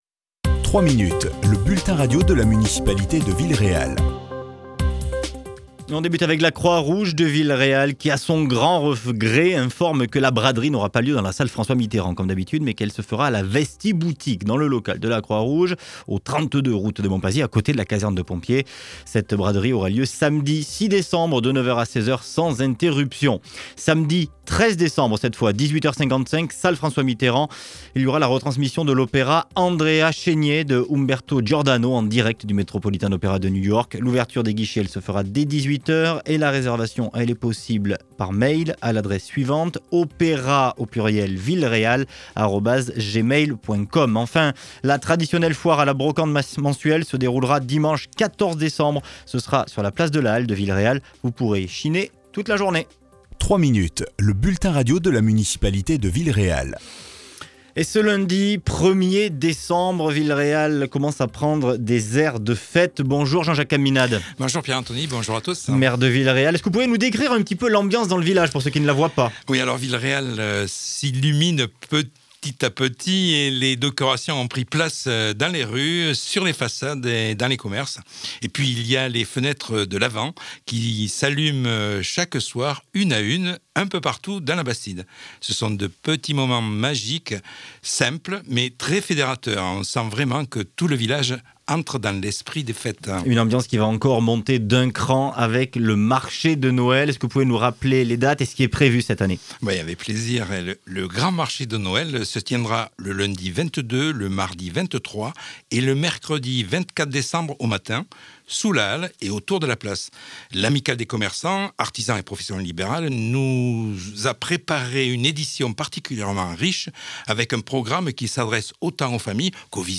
Villeréal se prépare à vivre un mois de décembre féerique, avec notamment un marché de Noël sur trois jours, organisé par l'Amicale des Commerçants. Les explications du maire, Jean-Jacques Caminade.